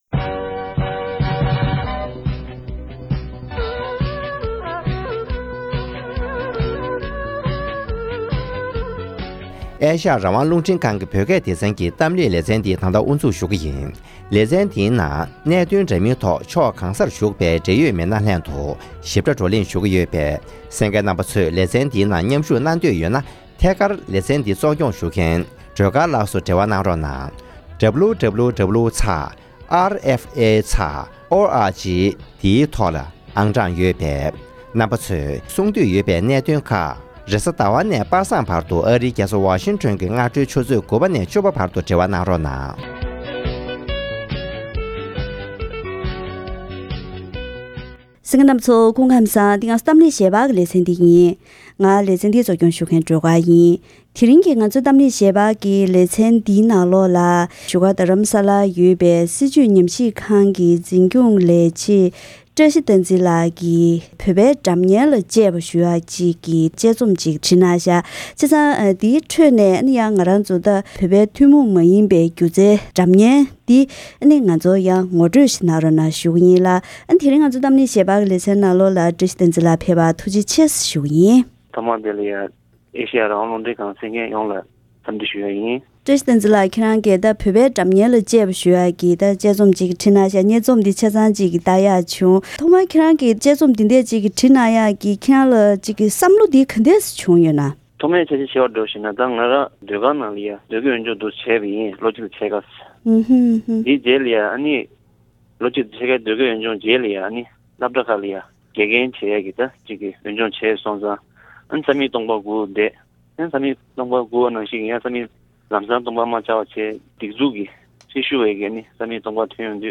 ཐེངས་འདིའི་གཏམ་གླེང་ཞལ་པར་ལེ་ཚན་ནང༌།